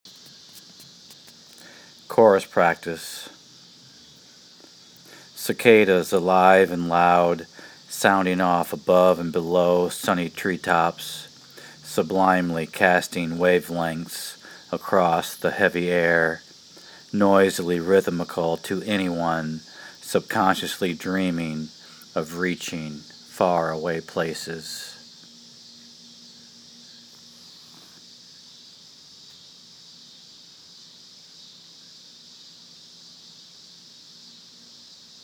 cicadas alive and loud sounding off above and below sunny treetops sublimely casting wavelengths across the heavy air noisily rhythmical to anyone subconsciously dreaming of reaching faraway places…